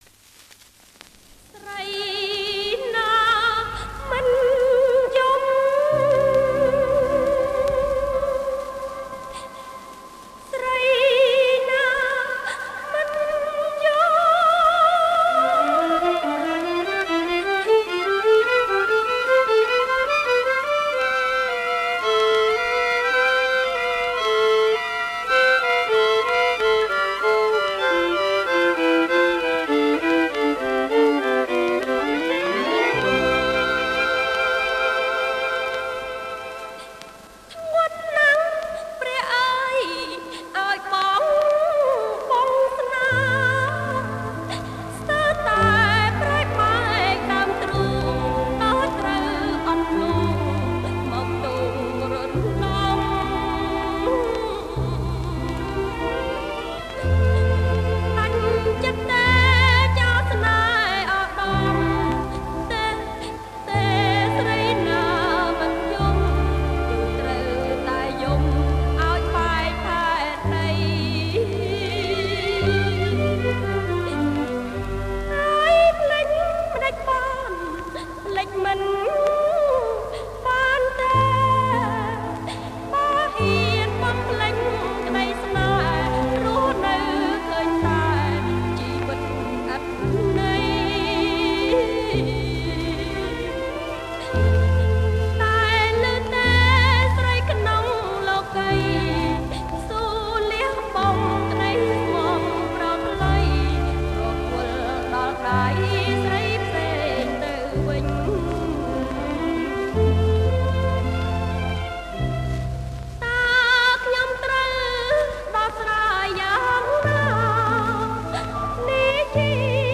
• ចង្វាក់ Slow Rock